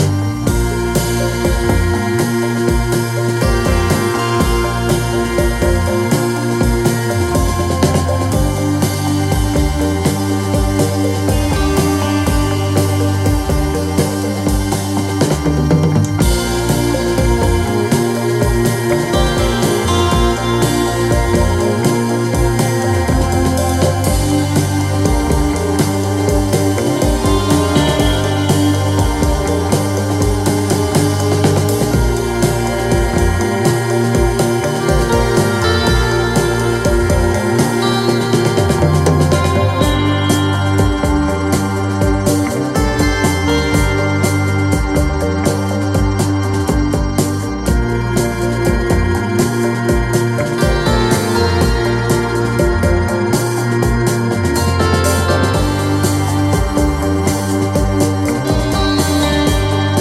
Heavy cinematic-funk 45